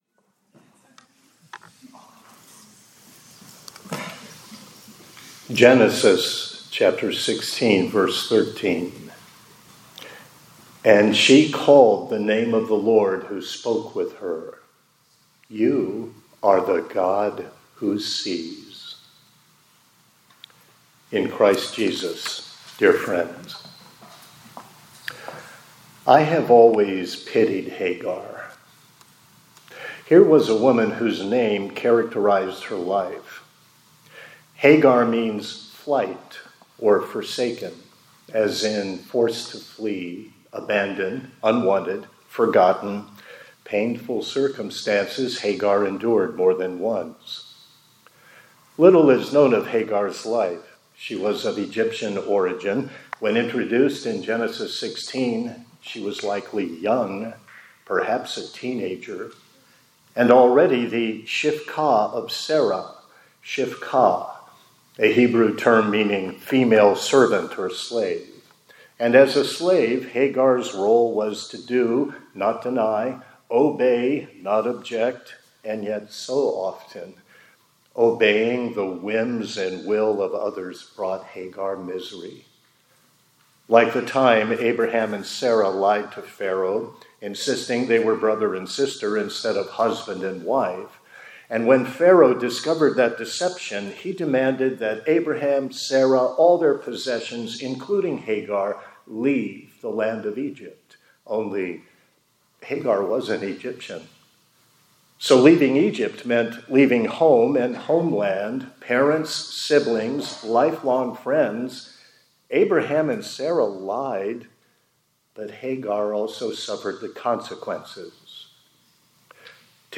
2025-09-30 ILC Chapel — The God Who Sees Me